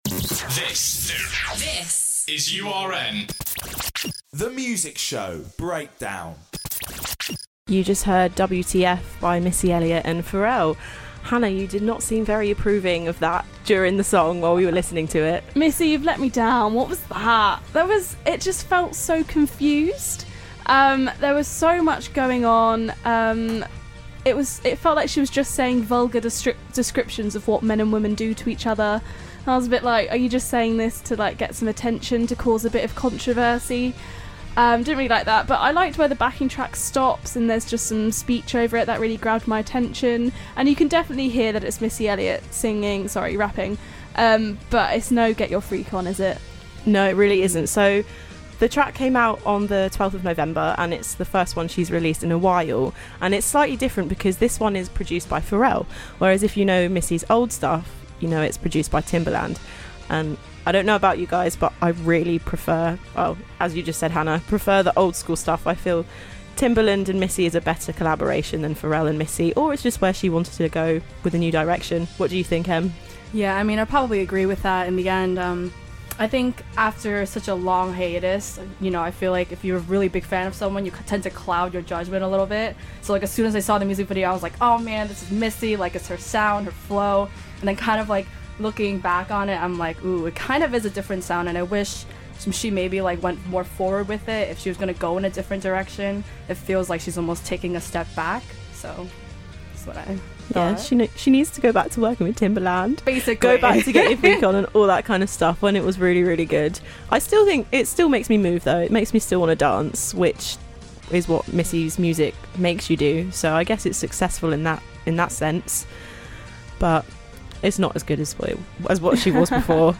Join The Music Show as we discuss Missy Elliott's latest release, Demi Lovato charging fans $10,000 for a meet and greet and listen to an exclusive live session with These Your Children.